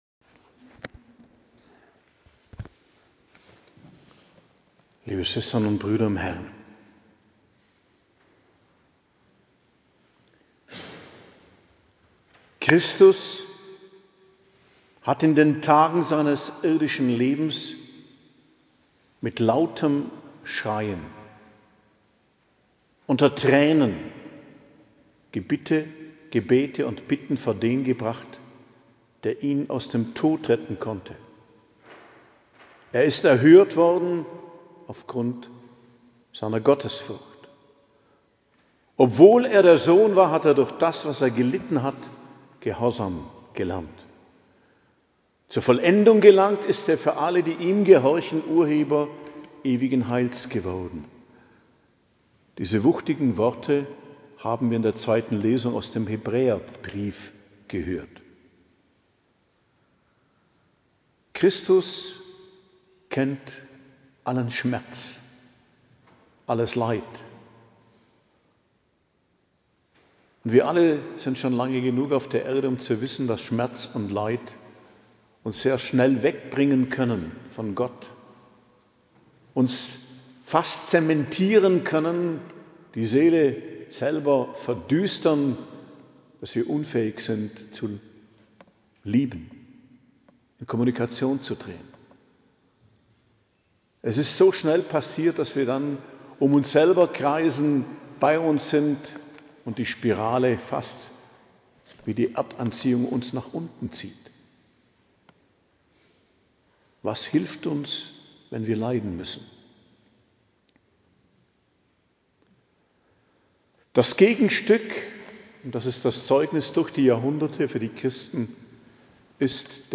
Predigt am Karfreitag, die Feier vom Leiden und Sterben Christi, 18.04.2025 ~ Geistliches Zentrum Kloster Heiligkreuztal Podcast